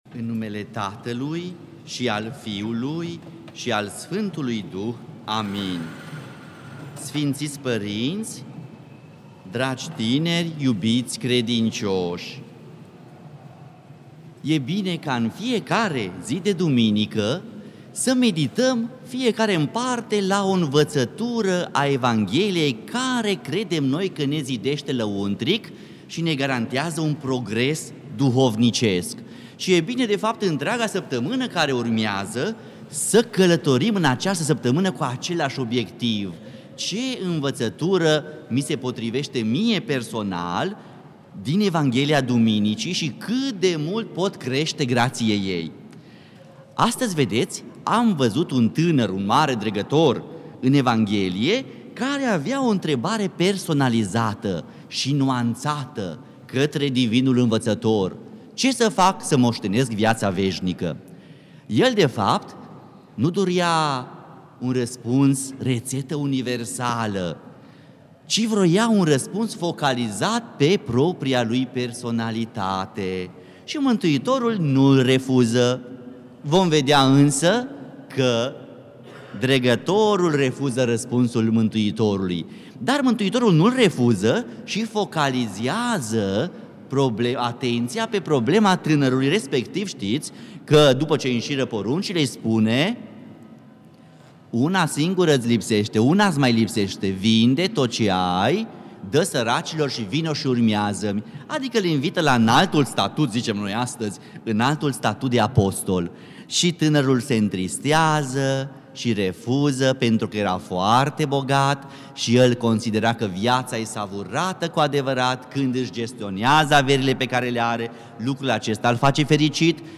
Predică la Duminica a 30-a după Rusalii